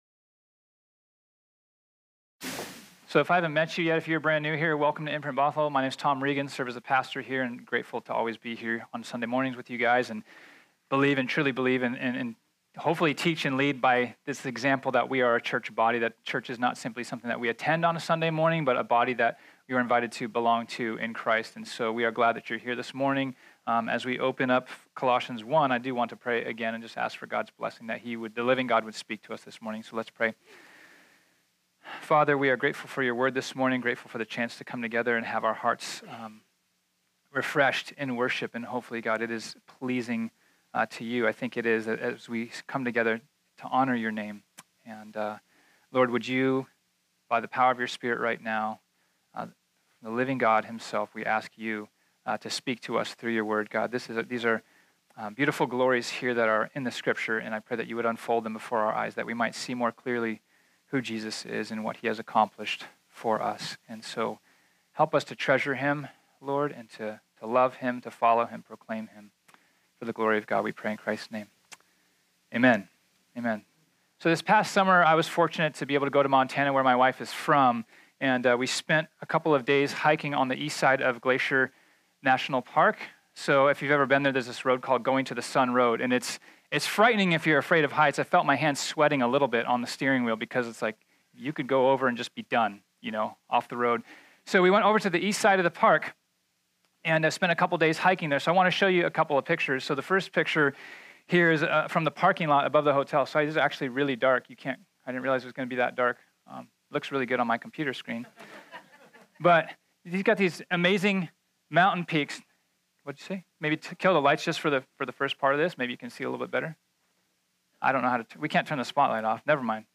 This sermon was originally preached on Sunday, September 23, 2018.